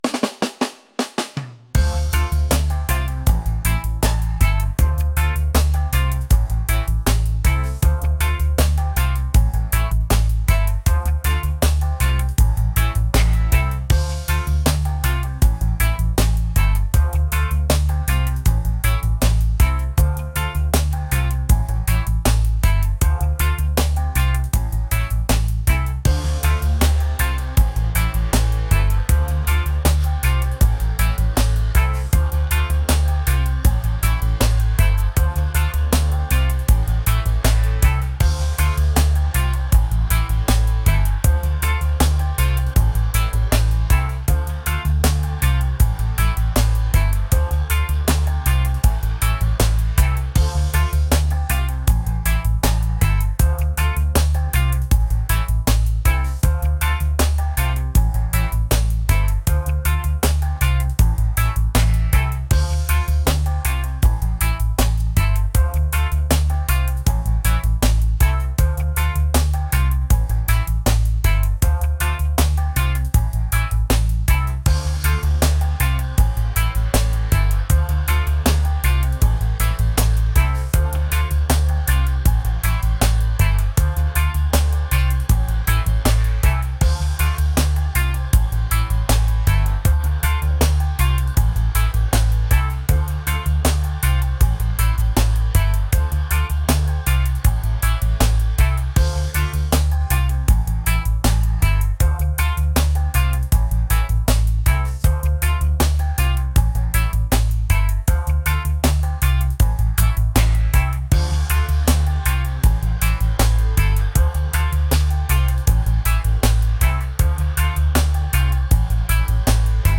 groovy | reggae | laid-back